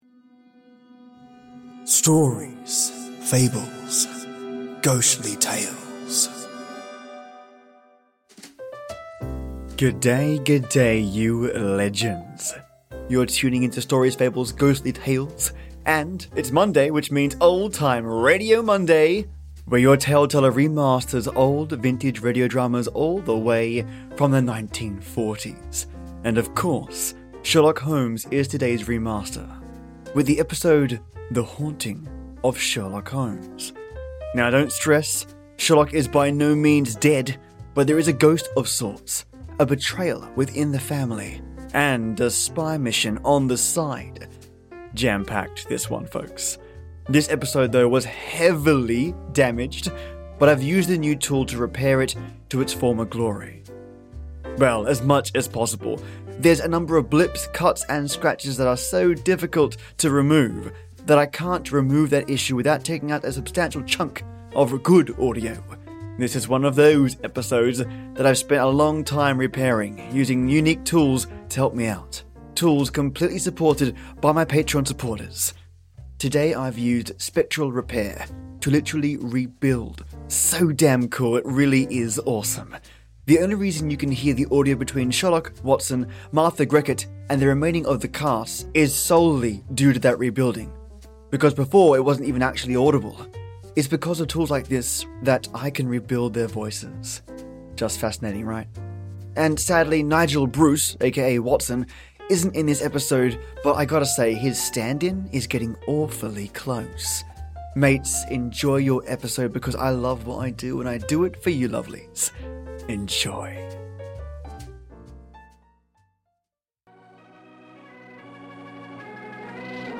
Your tuning into Old Time Radio Monday where your Tale Teller remasters old vintage radio drama’s all the way from the 1940’s. And of course, Sherlock Holmes is today’s remaster – with the episode The Haunting of Sherlock Holmes. Now don’t stress, Sherlock is by no means dead, but there is a ghost of sorts….a betrayal within the family, and spy mission on the side. Folks this episode was HEAVILY damaged, but I’ve used the new tool to repair it to its former glory – I hope you all love the episode mates!